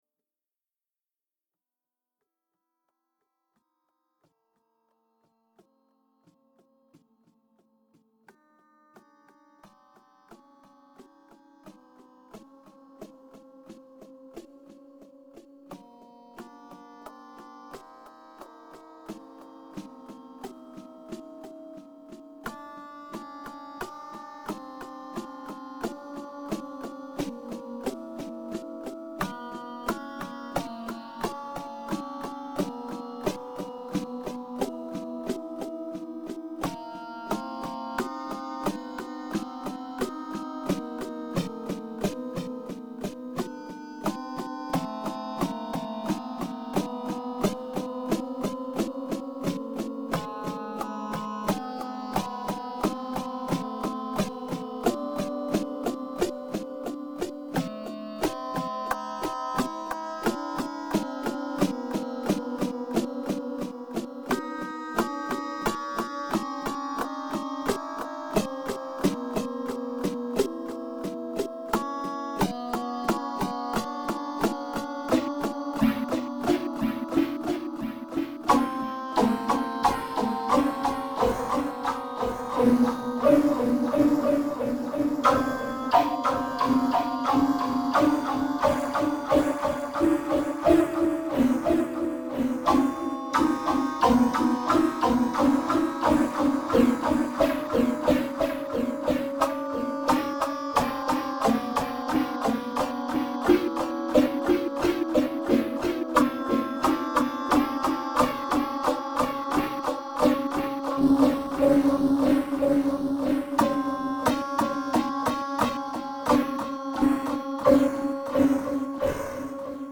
Morphing a lullaby. http